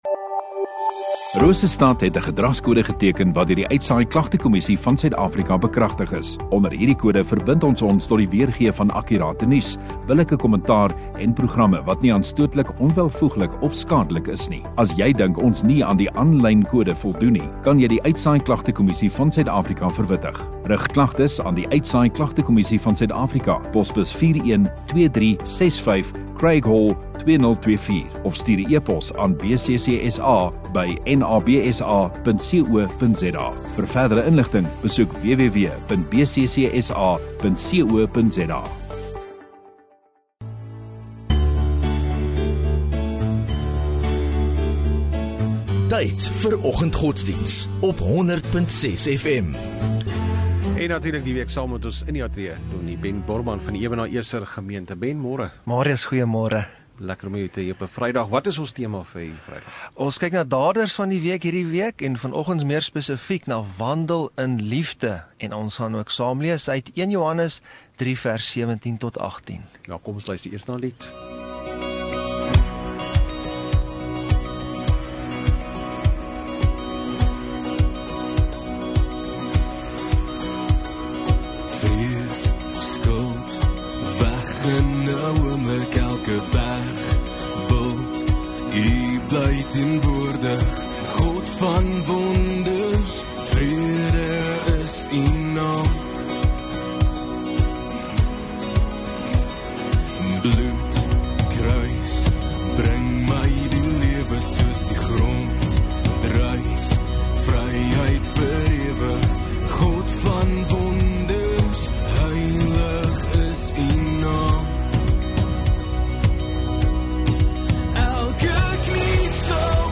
17 Jan Vrydag Oggenddiens